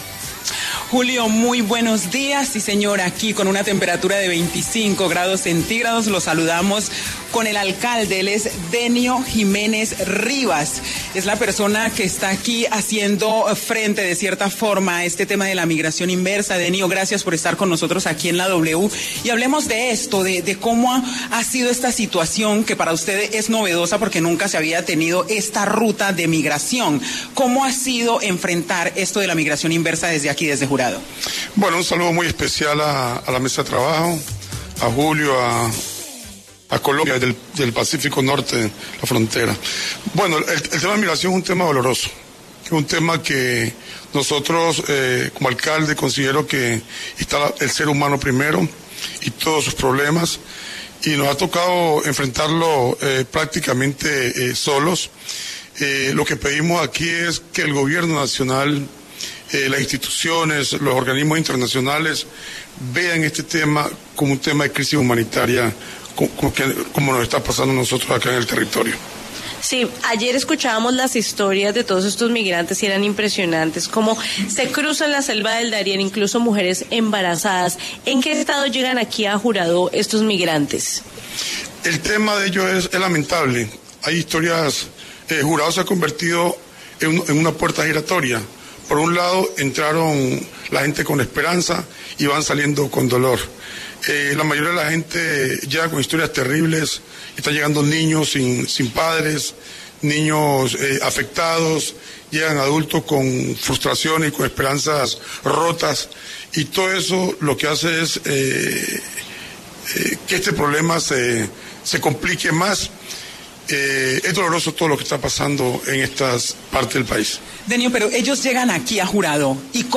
Denio Jiménez Rivas, alcalde de Juradó, Chocó, habló en los micrófonos de La W sobre la migración inversa que ha traído preocupación y crisis para el municipio.